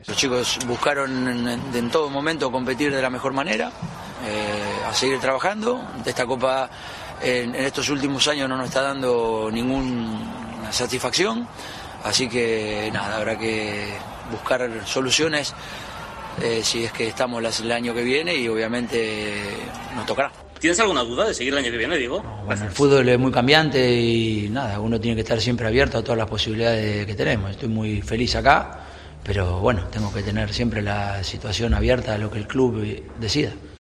EN RUEDA DE PRENSA
El entrenador del Atlético de Madrid sembró la duda con una de sus respuestas en rueda de prensa tras la eliminación del equipo ante el Cornellá.